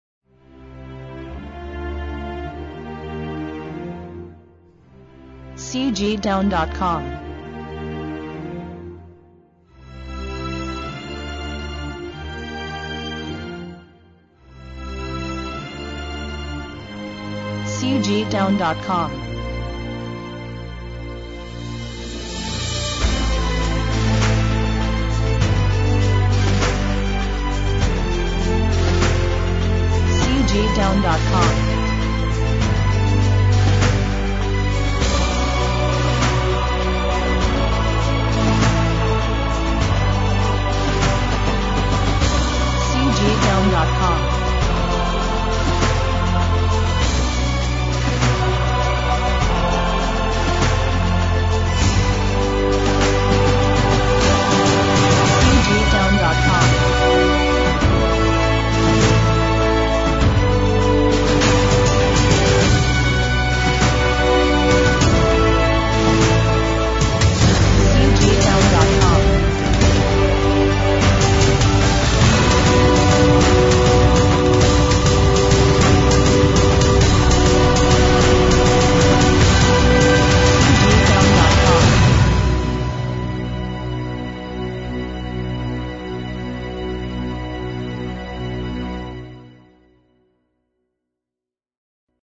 1970-01-01 辉煌感动